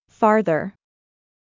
• /ˈfɑːrðər/（アメリカ英語：ファーrザー
• 「r」がしっかり入る
• 「far」がベースになっており、「距離感」を含む音の流れ
📌「ファーザー」と「farther」の唯一の違いは “r” の有無。ネイティブでも聞き間違えるほど似ています！